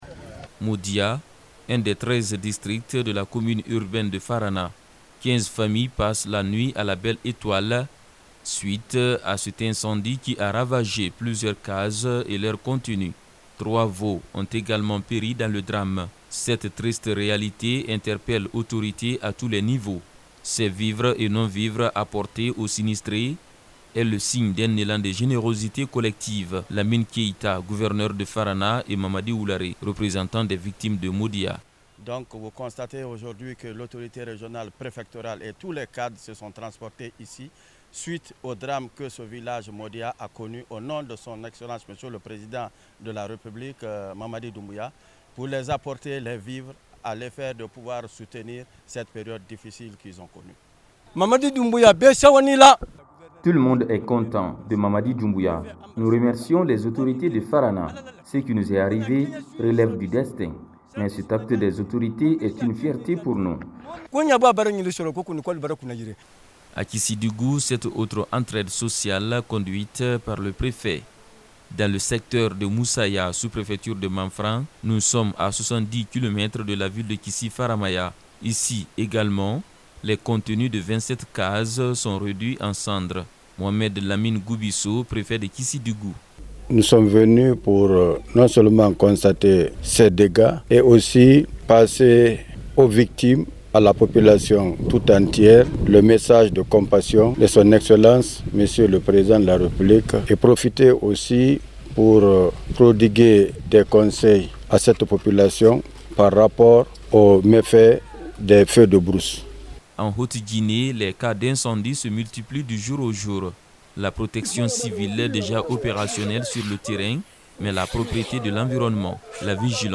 reportage👇🏿